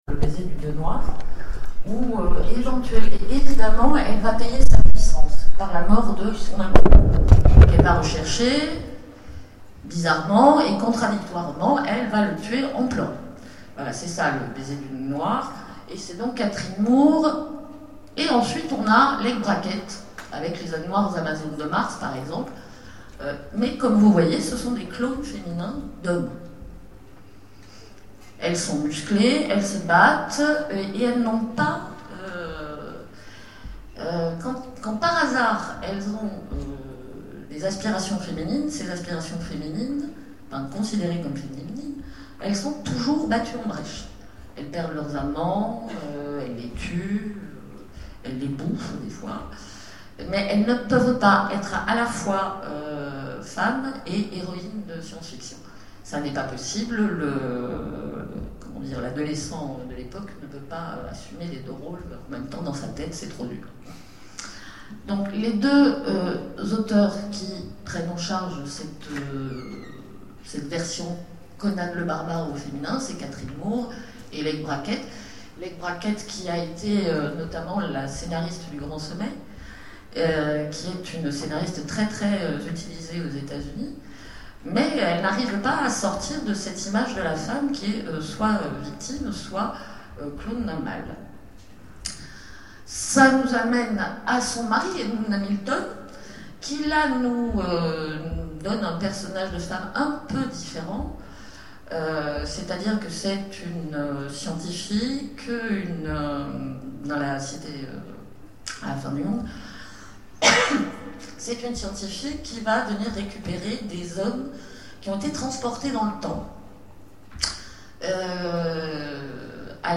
Mots-clés Femme Conférence Partager cet article